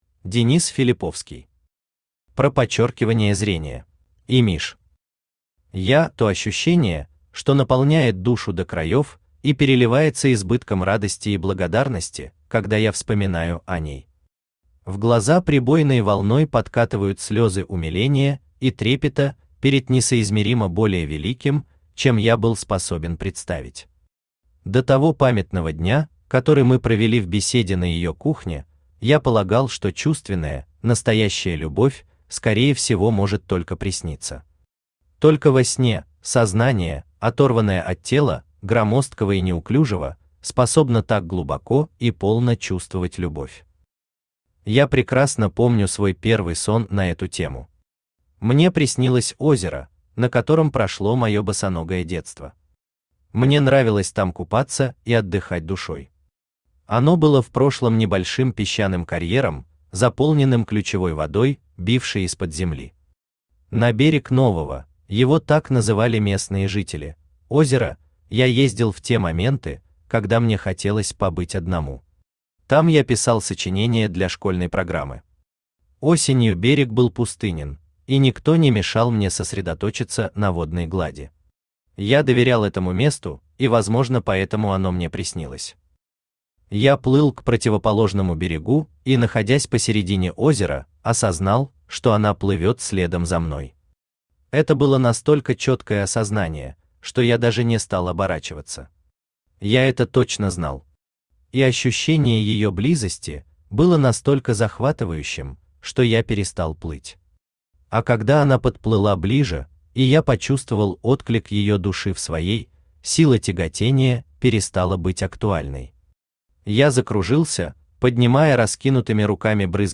Аудиокнига Про_зрение | Библиотека аудиокниг
Aудиокнига Про_зрение Автор Денис Олегович Филиповский Читает аудиокнигу Авточтец ЛитРес.